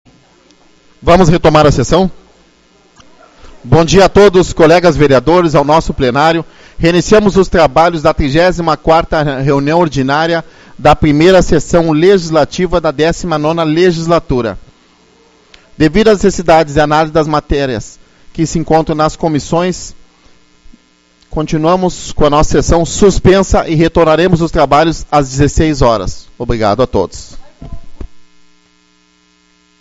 06/06 - Reunião Ordinária